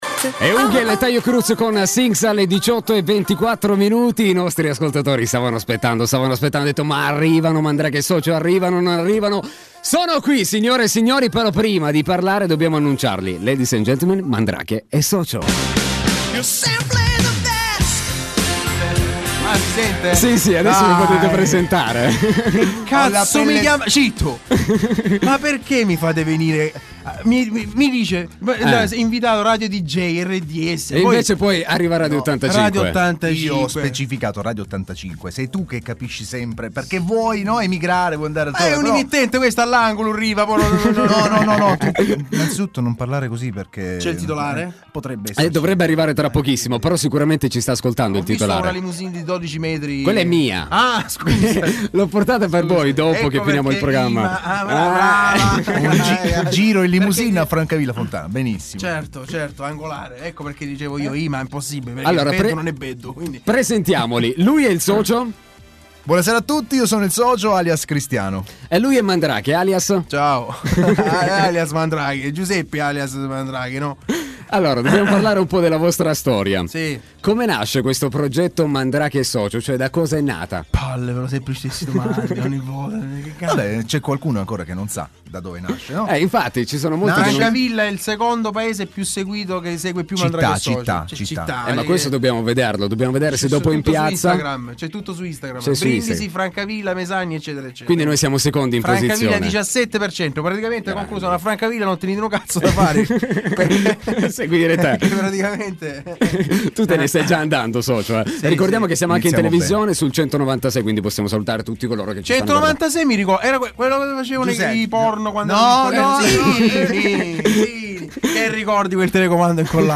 Interviste Top Player